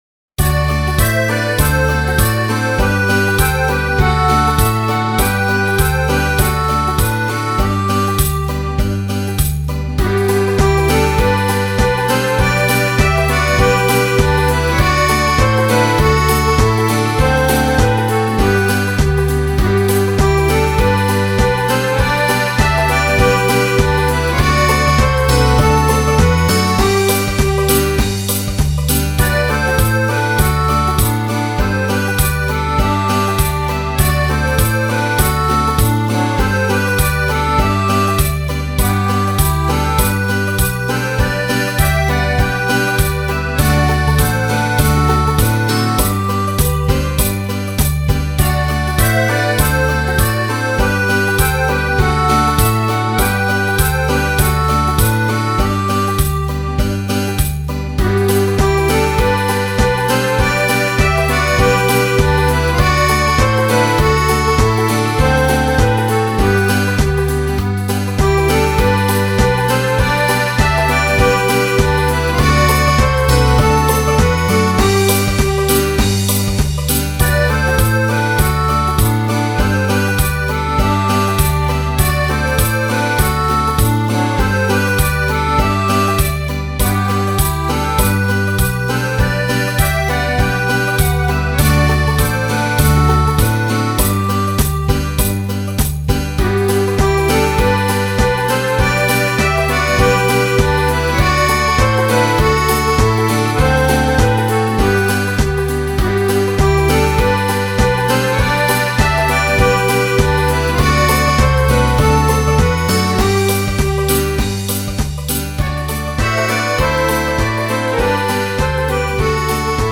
Text ♫ Playback ♫ Gitarre ♫ Akkorde